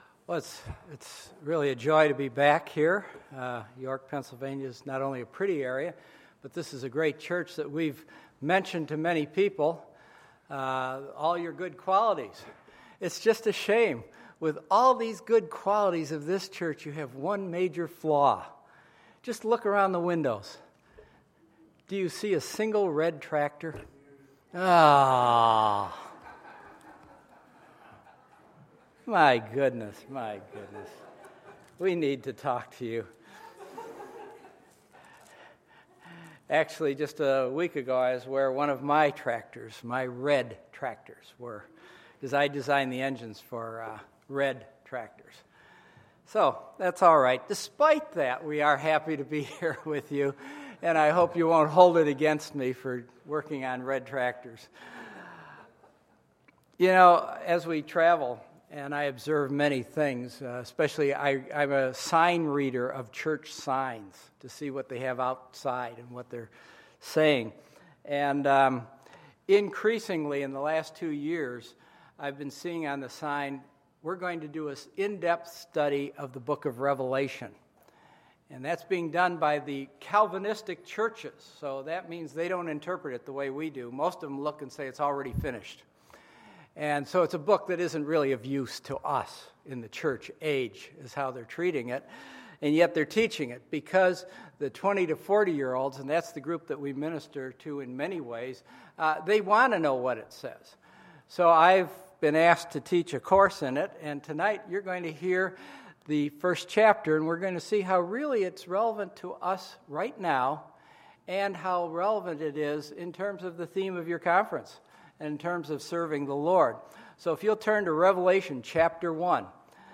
Sunday, September 27, 2015 – Missions Conference Sunday Evening Service